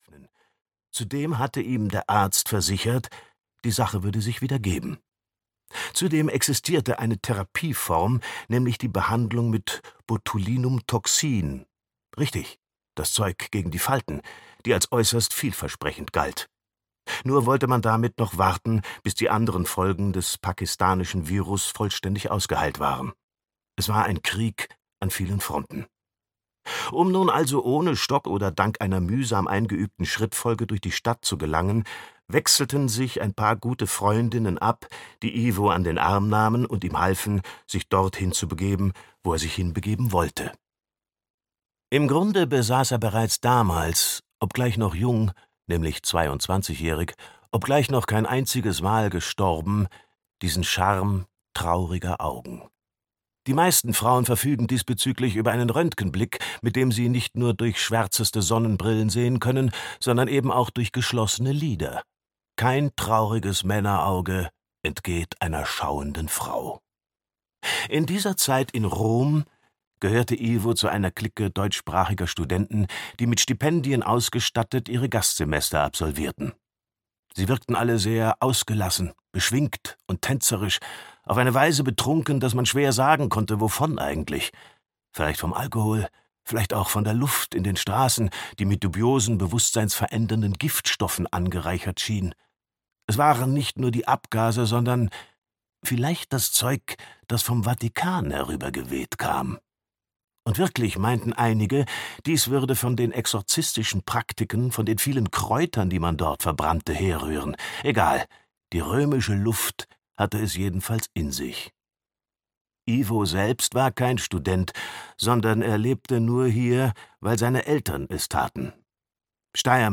Die Haischwimmerin - Heinrich Steinfest - Hörbuch